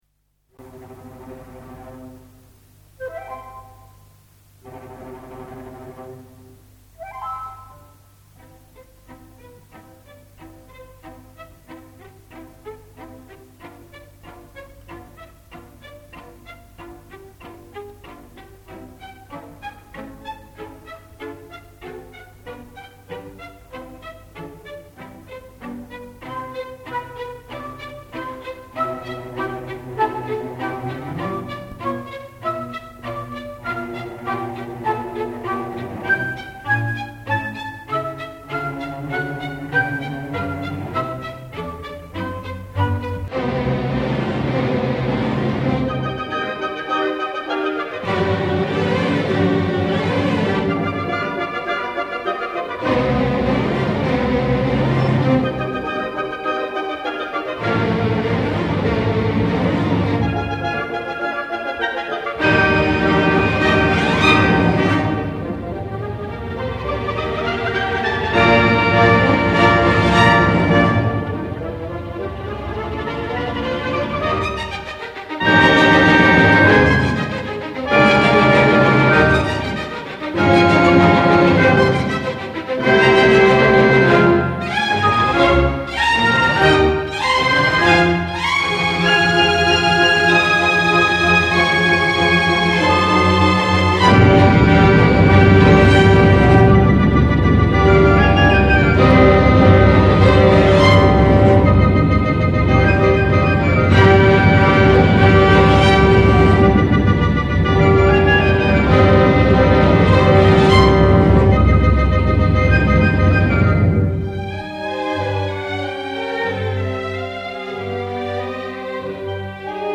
Για παράδειγμα, ο Ροσσίνι , ένας σπουδαίος συνθέτης, σε ένα μέρος από το έργο του «Ο κουρέας της Σεβίλλης» περιγράφει με τη μουσική του τη βροχή και την καταιγίδα.
Ο δάσκαλός σου θα βάλει να ακούσεις αυτό το μέρος κι εσύ θα πρέπει να αναγνωρίσεις τα σημεία όπου ακούς τις ψιχάλες, τις βροντές και τους κεραυνούς!